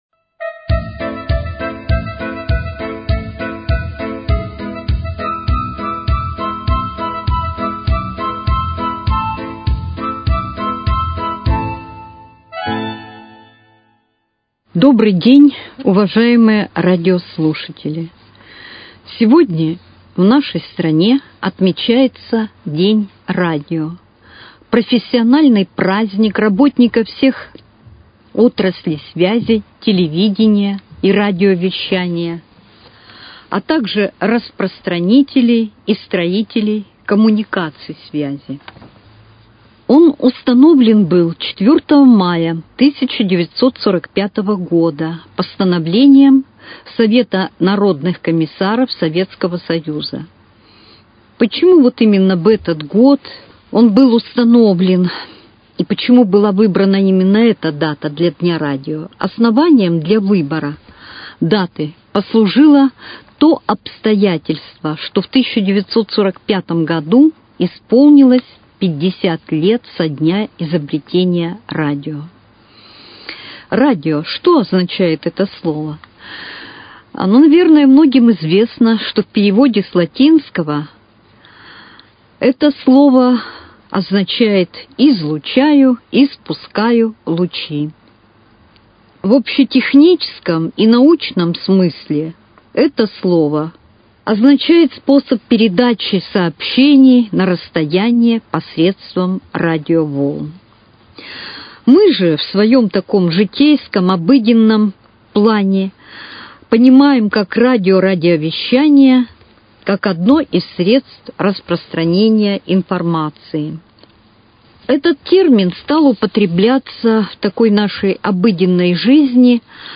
Передача ко Дню Радио.